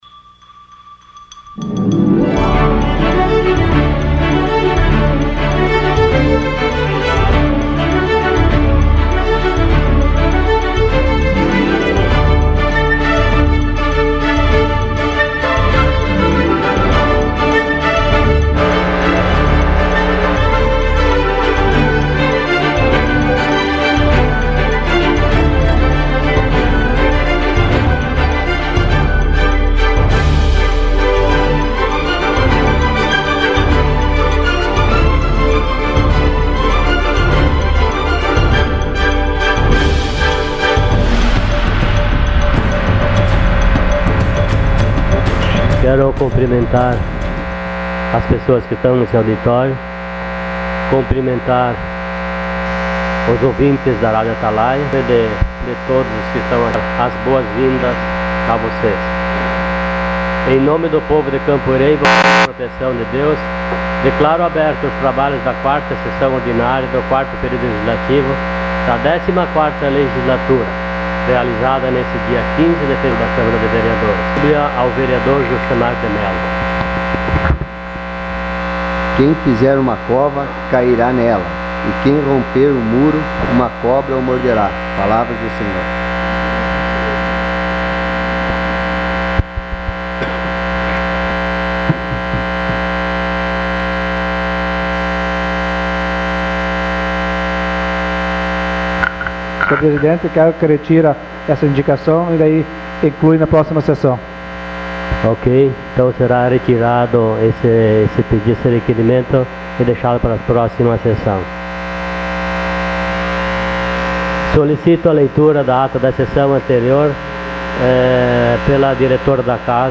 Sessão Ordinária dia 15/02/2016